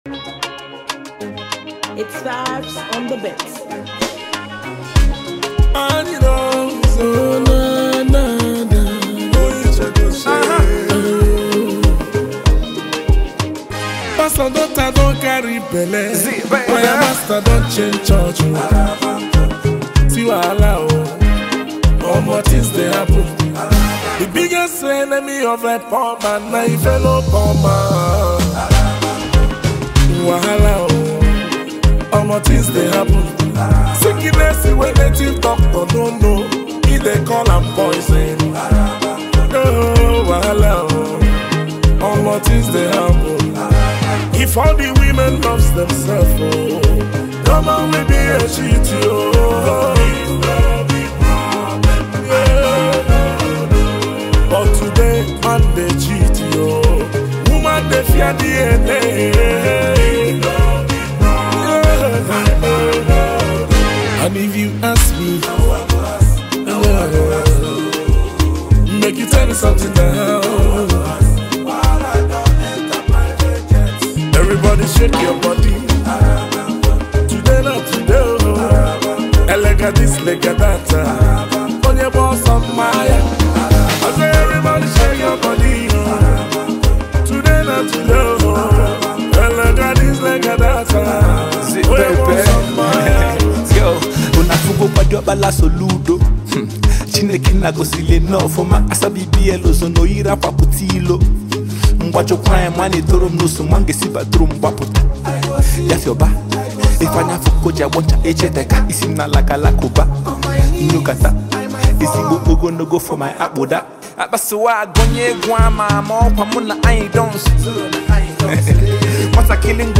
Highlife royalty meets street-wise rap